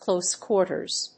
clóse quárters /klóʊs‐klˈəʊs‐/
発音記号
• / klóʊs‐(米国英語)
• / klˈəʊs‐(英国英語)